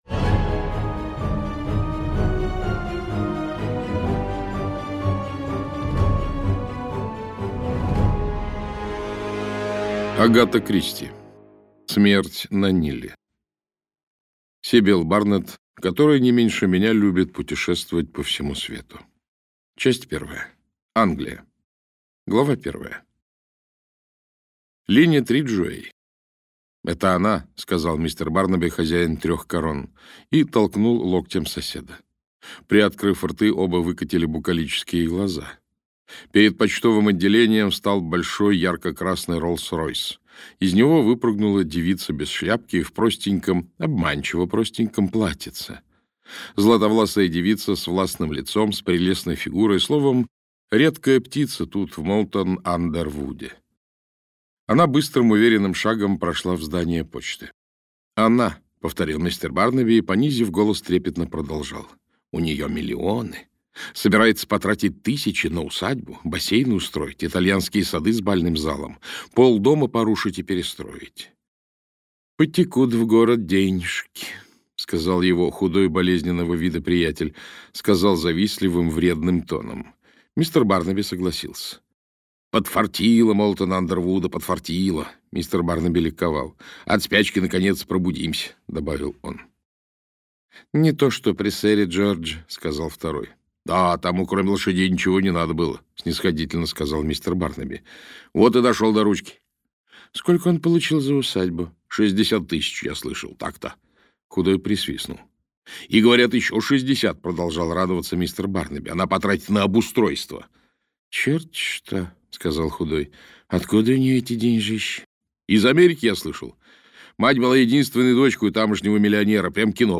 Аудиокнига Смерть на Ниле - купить, скачать и слушать онлайн | КнигоПоиск